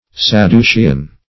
Sad`du*ce"an, a.